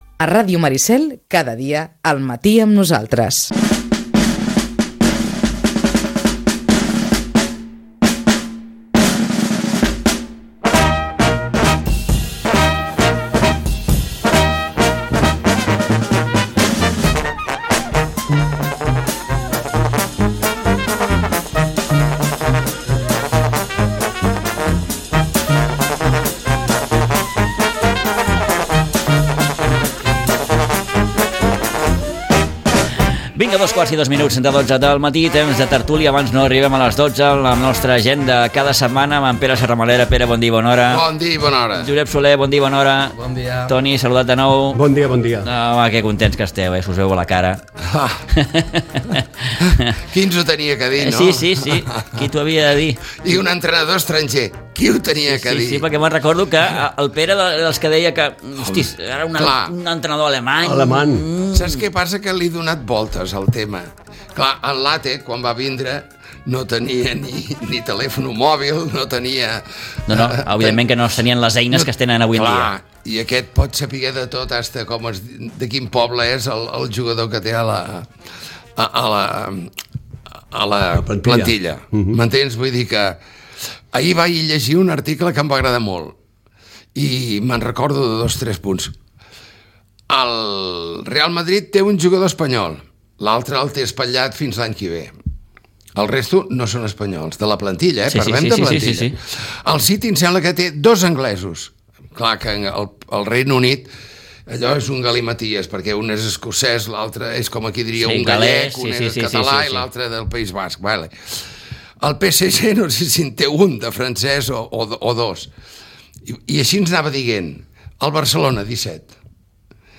La tertúlia esportiva
Tanquem setmana tot xerrant amb els nostres tertulians de sempre.